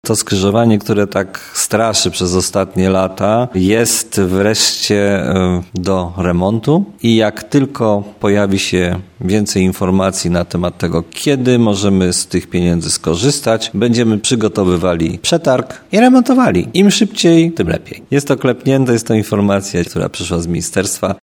Mówi prezydent Tarnobrzega, Dariusz Bożek.